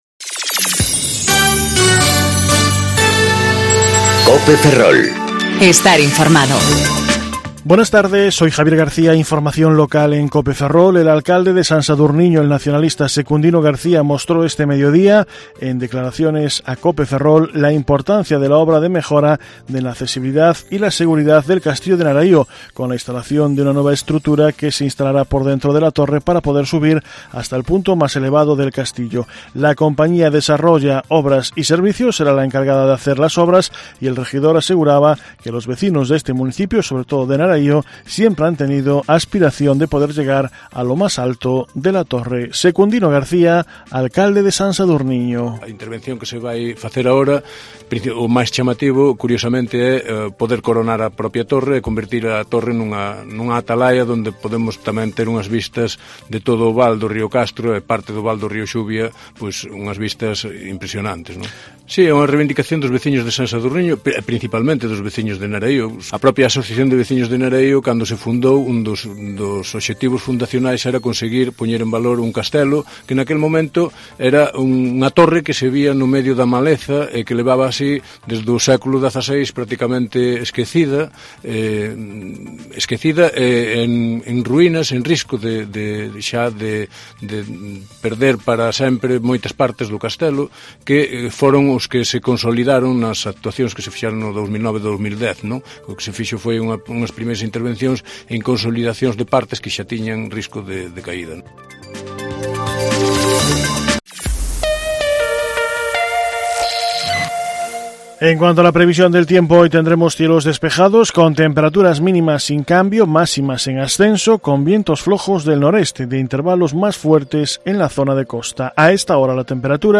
Informativo Mediodía Cope Ferrol 22/08/2019 (De 14.20 a 14.30 horas)